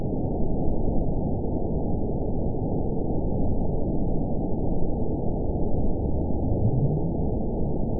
event 917842 date 04/19/23 time 01:48:02 GMT (2 years, 7 months ago) score 9.11 location TSS-AB06 detected by nrw target species NRW annotations +NRW Spectrogram: Frequency (kHz) vs. Time (s) audio not available .wav